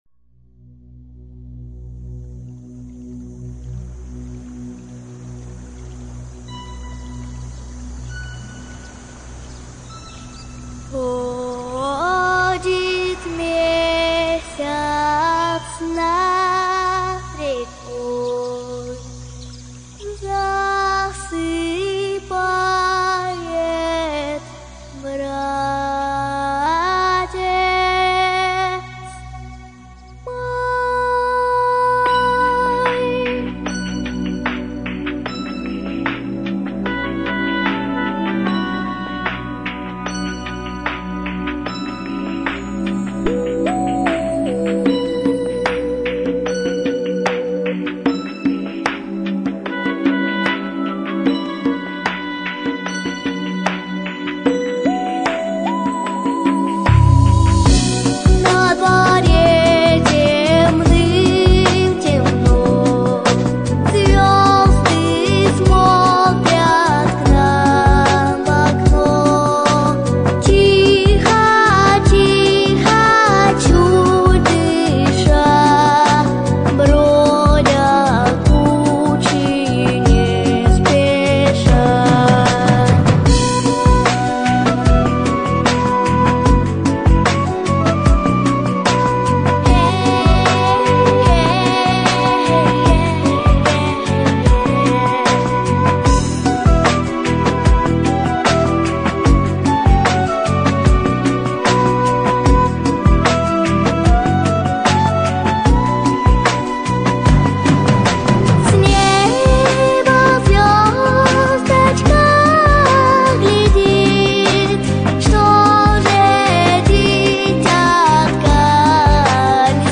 🎶 Детские песни / Колыбельные песни